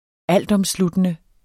Udtale [ -ˌʌmˌsludənə ]